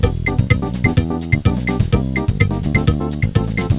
samba3[2].wav